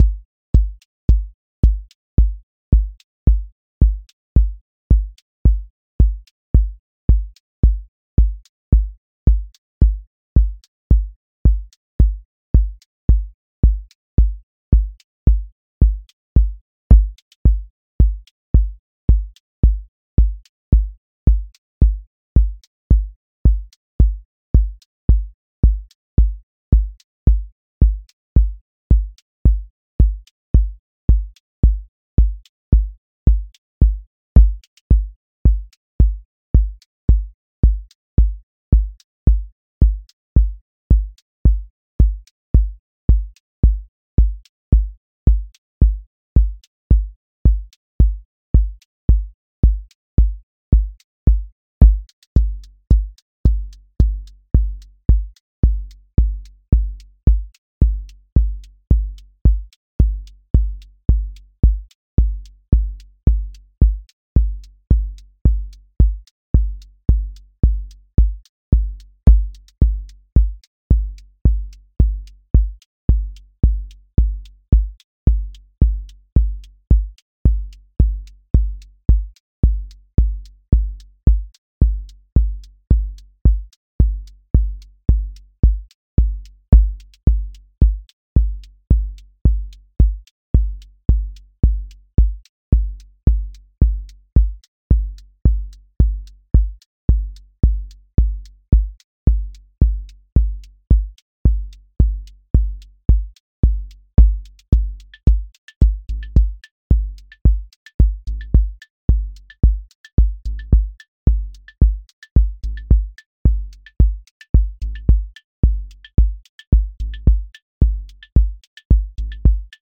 QA Listening Test house Template: four_on_floor
120-second house song with grounded sub, counter motion, a bridge lift, and a clear return
• voice_kick_808
• voice_hat_rimshot
• voice_sub_pulse
• motion_drift_slow
• tone_warm_body